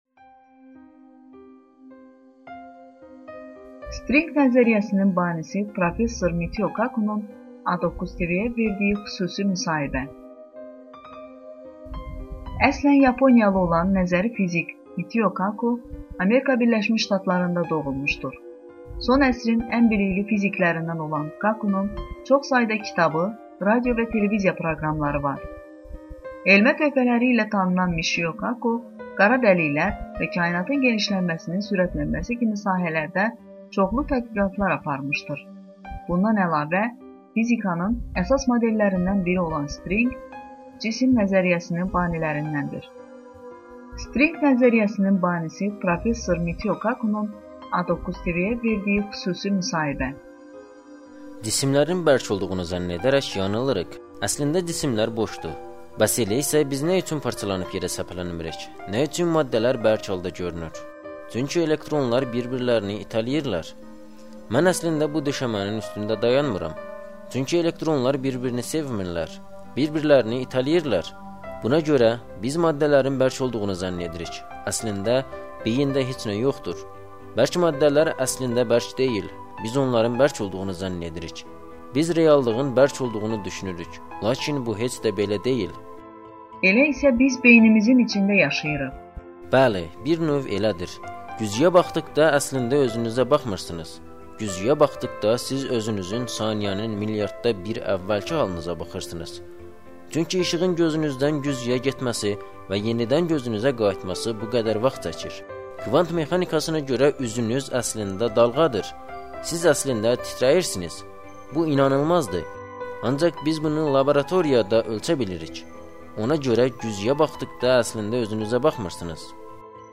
“STRİNQ” nəzəriyyəsinin banisi prof. Mitio Kakunun A9 TV-yə verdiyi xüsusi müsahibə